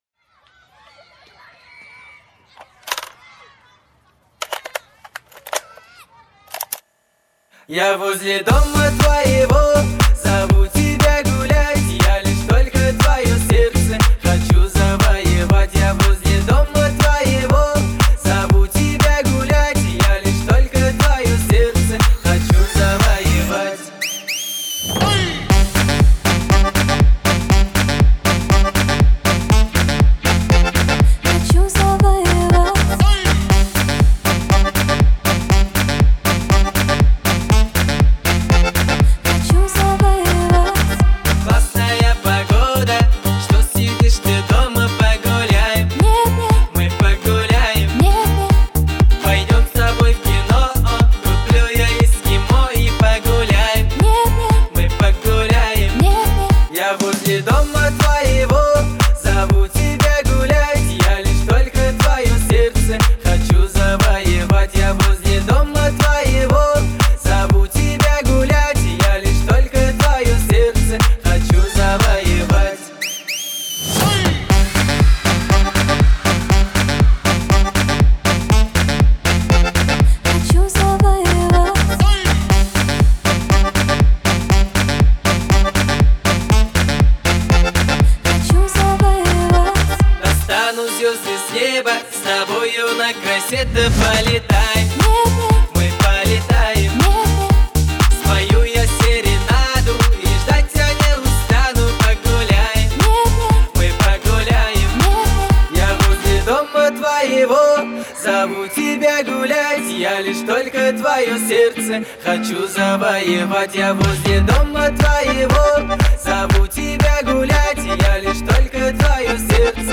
мелодичным вокалом и эмоциональной подачей